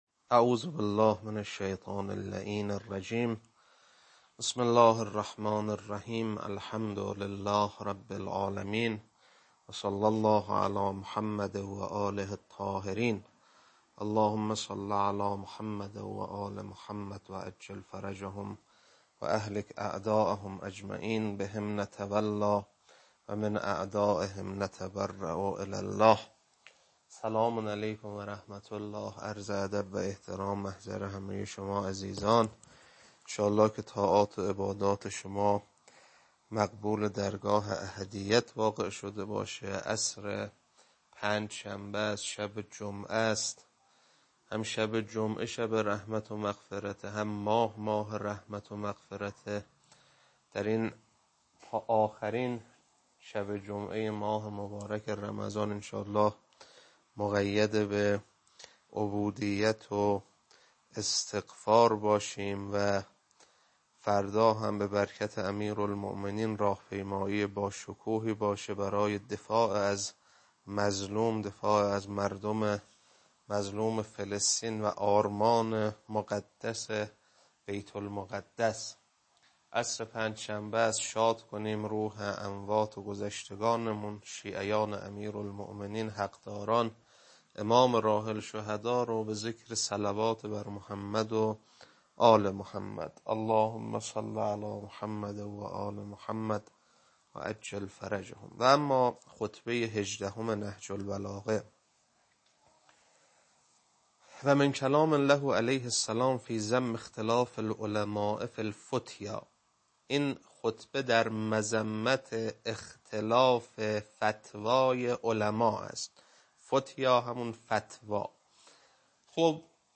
خطبه 18.mp3